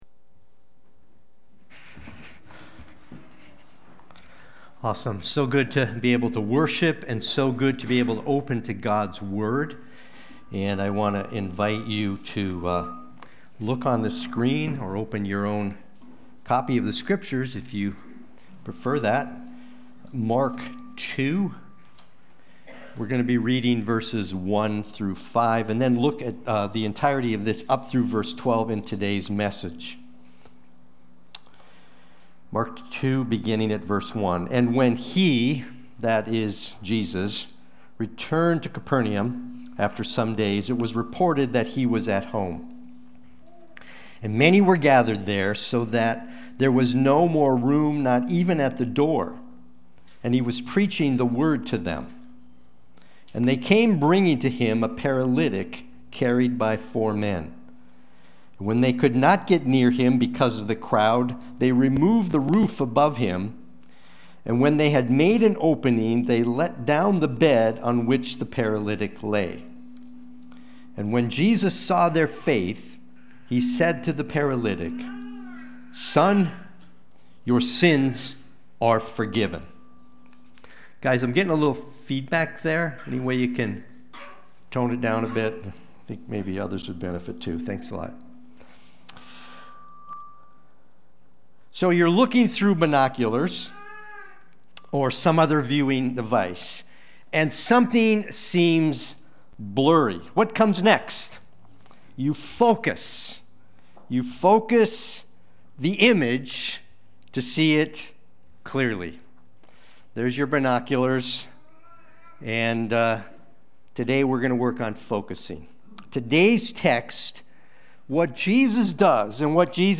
Watch the replay or listen to the sermon.
Sunday-Worship-main-11925.mp3